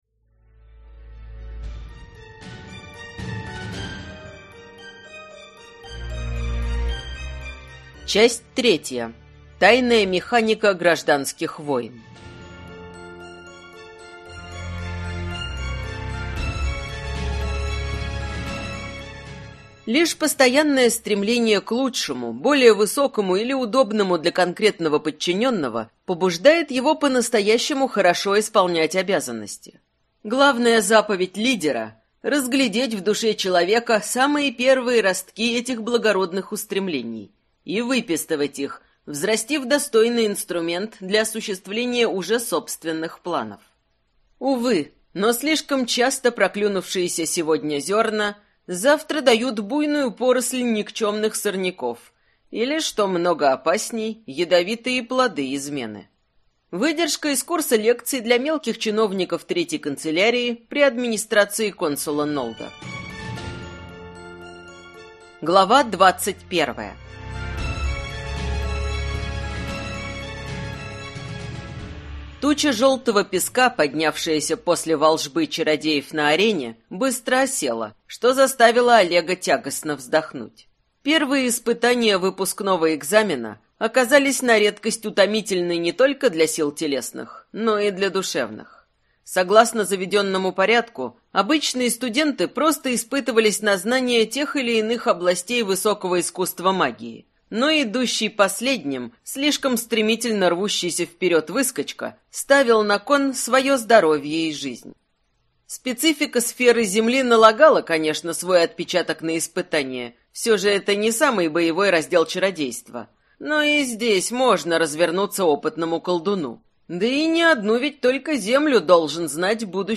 Аудиокнига Наемник Его Величества. Часть 3-я | Библиотека аудиокниг